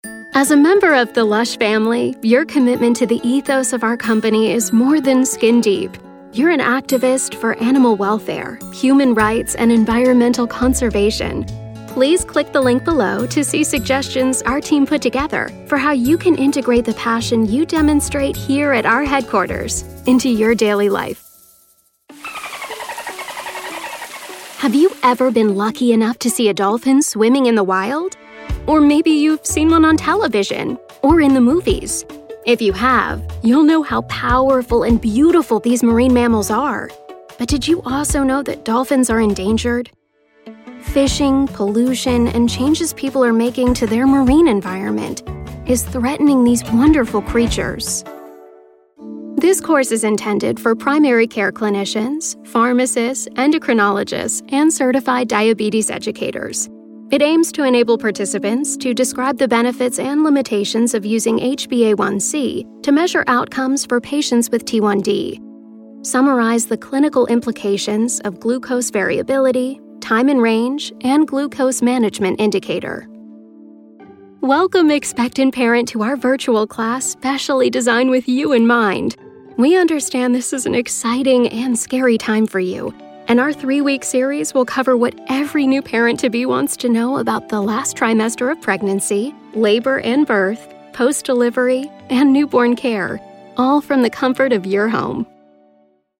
Female
American English (Native)
Bright, Bubbly, Confident, Cool, Engaging, Friendly, Natural, Warm, Versatile, Young
The Girl Next Door, warm, friendly and conversational voice you know and trust.
Narration.mp3
Microphone: TLM 103 and Sennheiser MKH 416